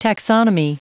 Prononciation du mot taxonomy en anglais (fichier audio)
Prononciation du mot : taxonomy